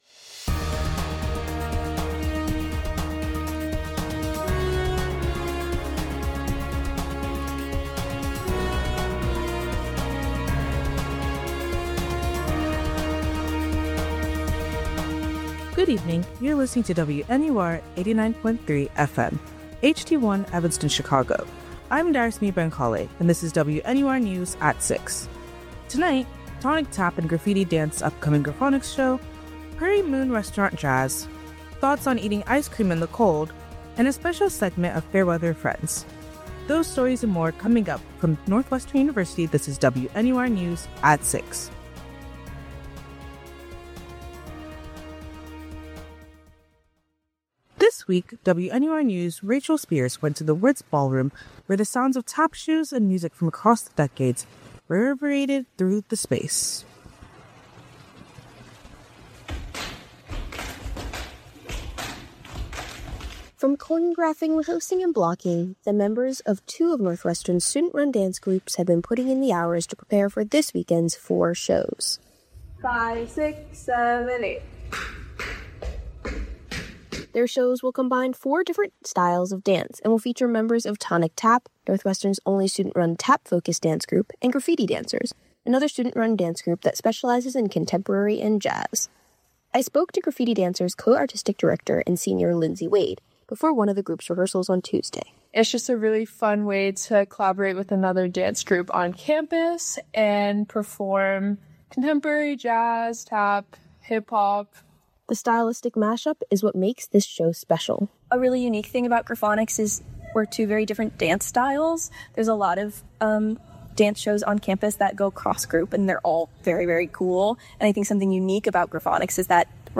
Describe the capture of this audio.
January 23, 2026: Tonik Tap and Grafitti dance upcoming Graffoniks show, Prairie Moon Restaurant jazz, thoughts on eating ice cream in the cold, and a special segment of Fair Weather Friends. WNUR News broadcasts live at 6 pm CST on Mondays, Wednesdays, and Fridays on WNUR 89.3 FM.